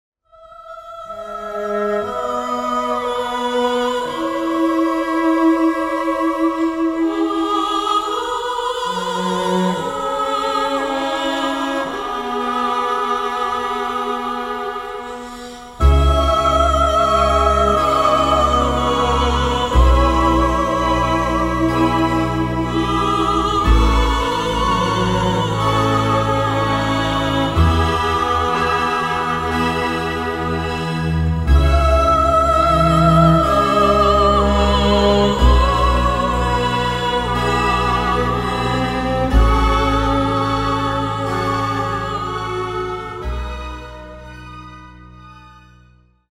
wordless female soprano voice
strong South American influence with pan pipes
ominous sounding tolling church bell